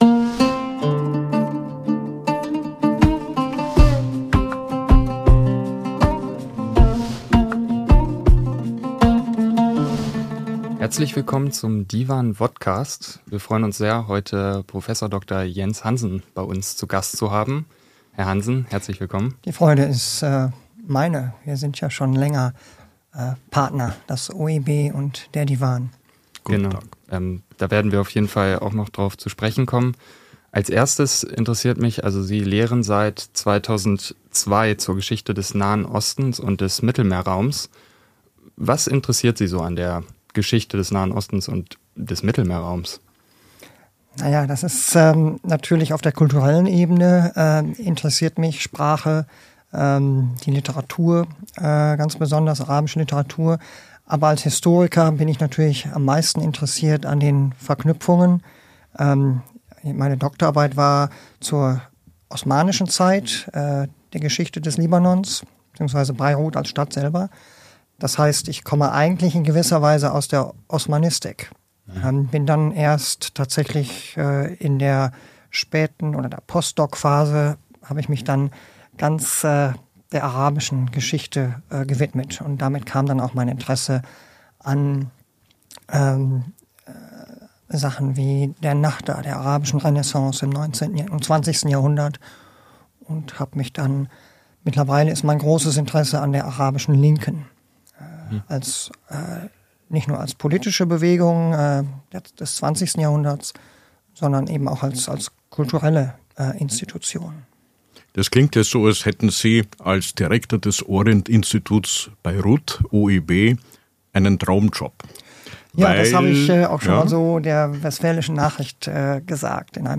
Wir haben das Interview am 6. September 2024 bei uns im Divan in Berlin aufgenommen und müssen im Hinblick auf die Äußerungen bezüglich Beiruts und des Libanons feststellen: Selten ist ein Gespräch so schnell und dramatisch gealtert.